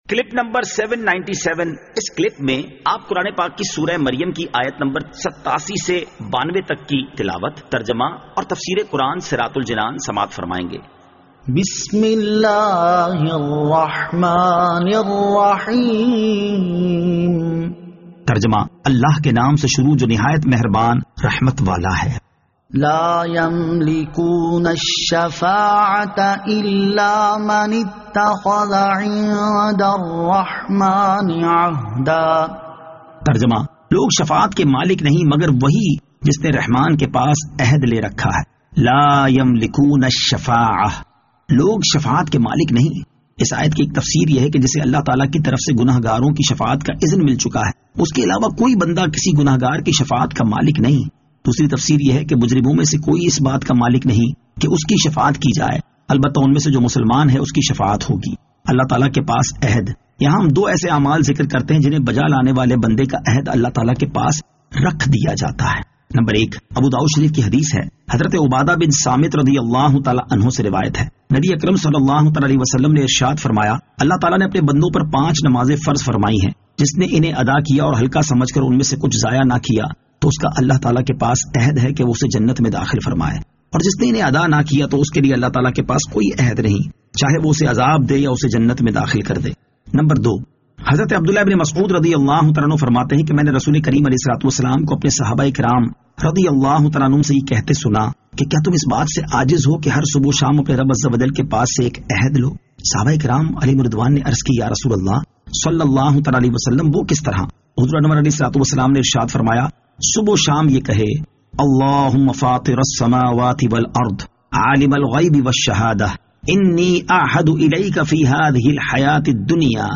Surah Maryam Ayat 87 To 92 Tilawat , Tarjama , Tafseer